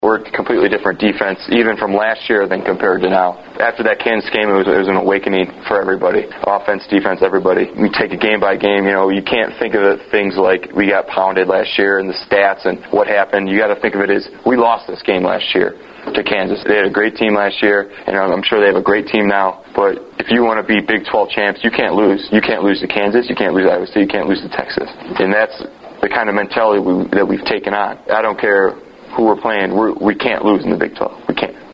The following are audio links to press conference interview segments with Husker players and NU head coach Bill Callahan.